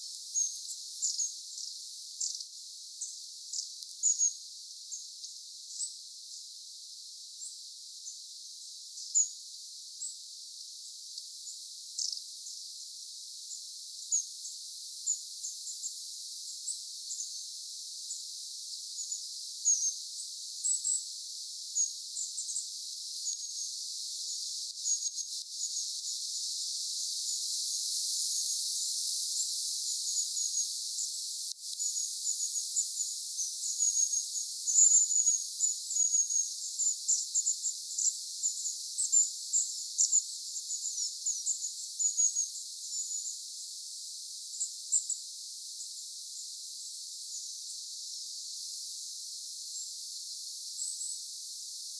Ei sent��n ollut ihan kuollutta, vaan oli el�m�� ja ihmetelt�v��. isompi kuva, joka aukeaa uuteen ikkunaan ��nite: pyrst�tiaisparvi ��ntelee
pyrstotiaisparvi_aantelee.mp3